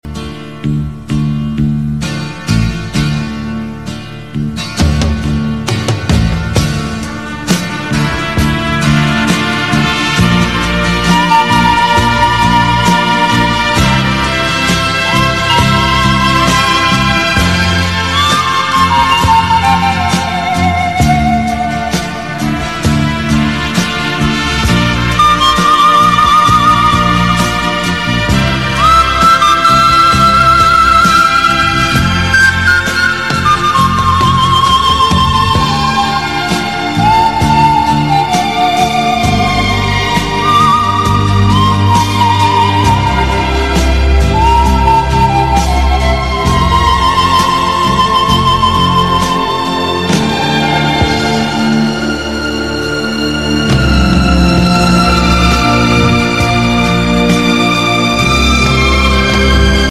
relaxing sounds